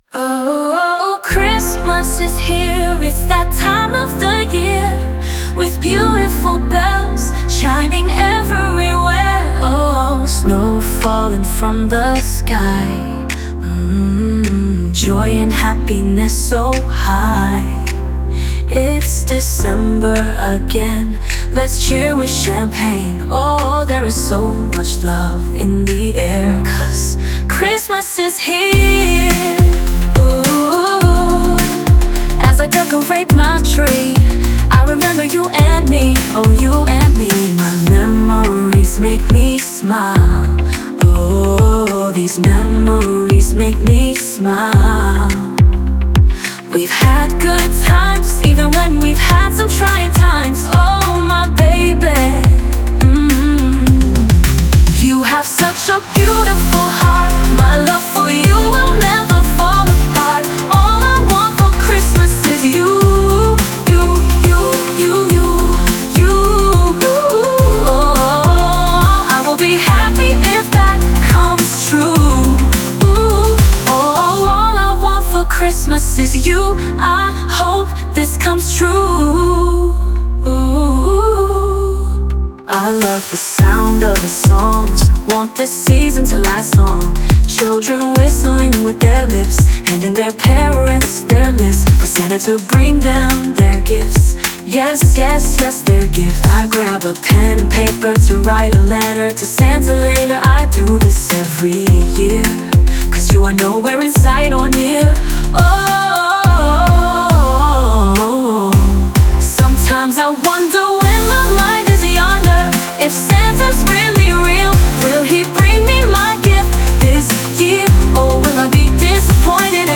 "Christmas Is Here" (holiday/pop)